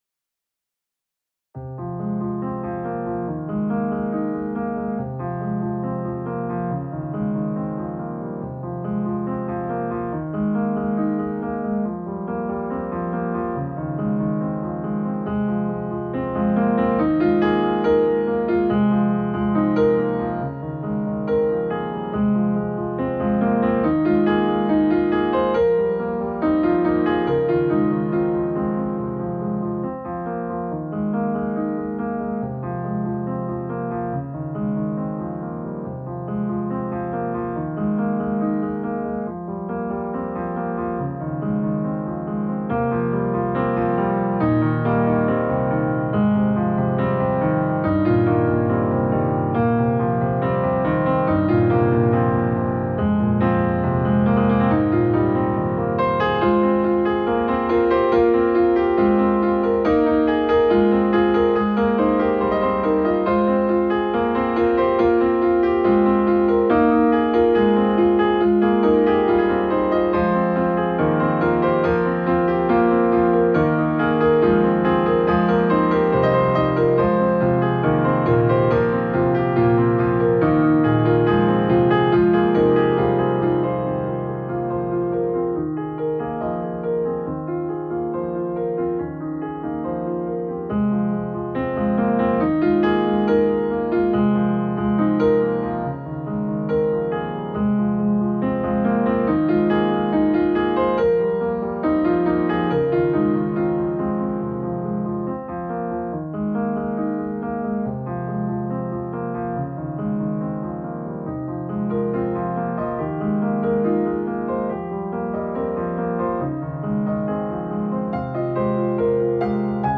Genre: filmscore, contemporarypiano.